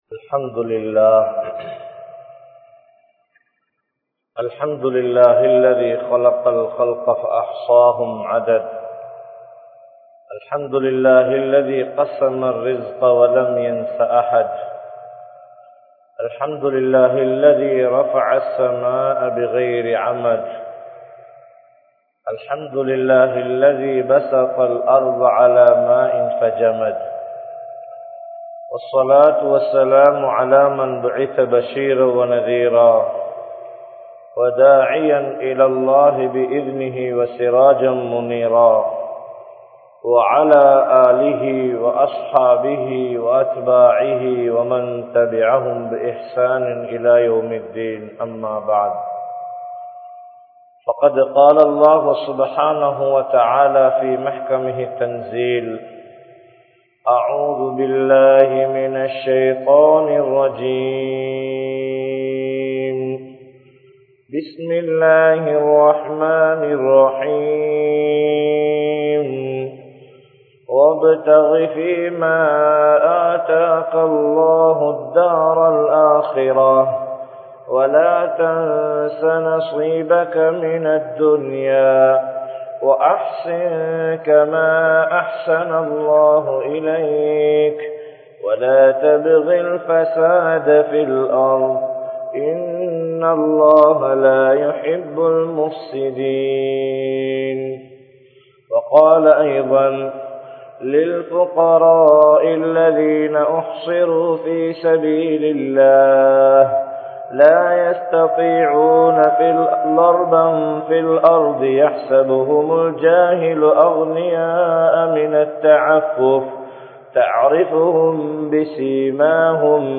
Islaamiya Paarvaiel Selvamum Ealmaium (இஸ்லாமிய பார்வையில் செல்வமும் ஏழ்மையும்) | Audio Bayans | All Ceylon Muslim Youth Community | Addalaichenai
Colombo 12, Aluthkade, Muhiyadeen Jumua Masjidh